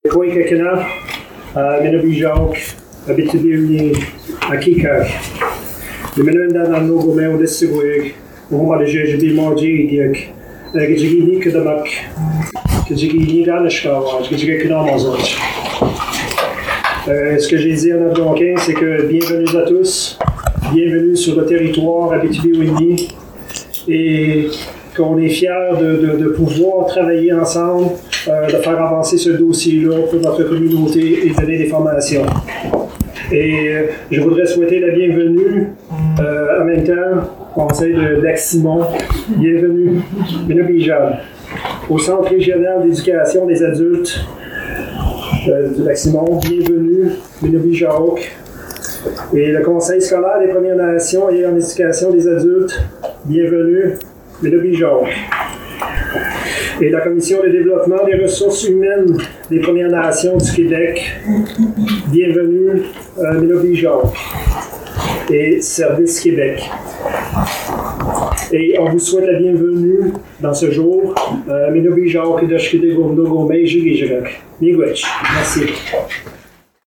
livrent des discours, pendant l’inauguration d'un nouveau centre d'éducation régional pour les adultes (CRÉA) dans la communauté.